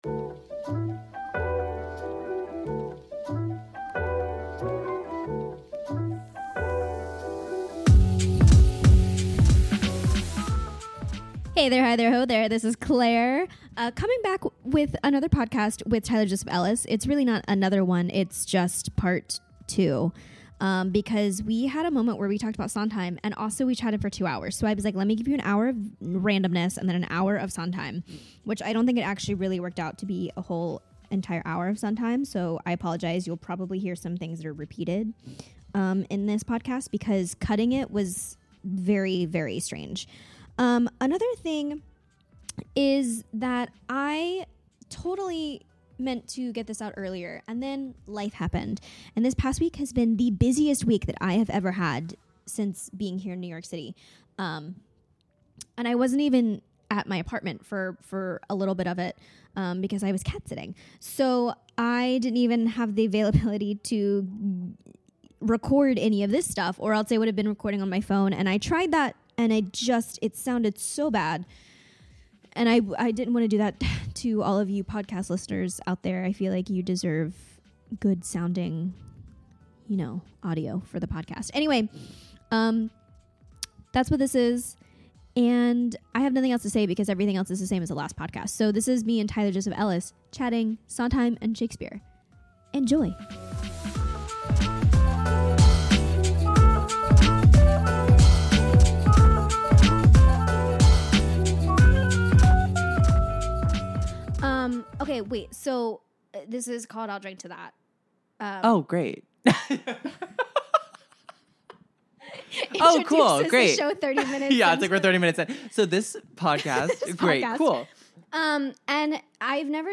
🎤 Shure SM58 with Focusrite